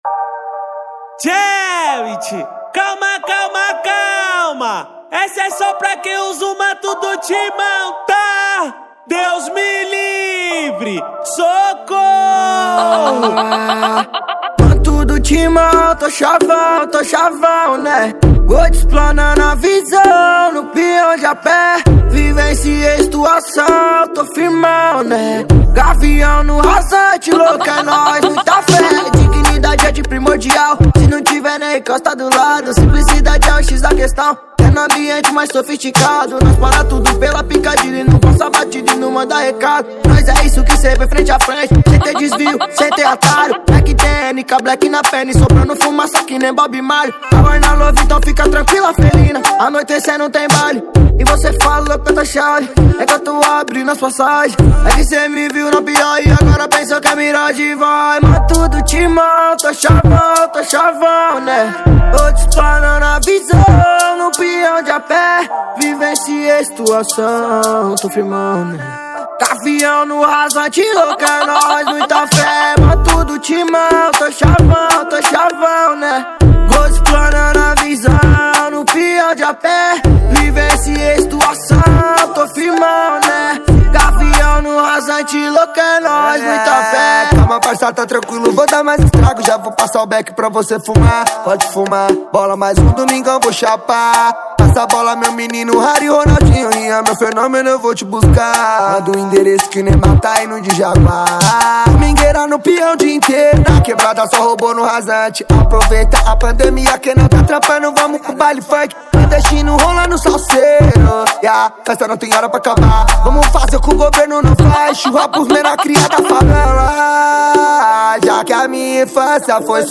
2024-12-04 15:53:57 Gênero: Funk Views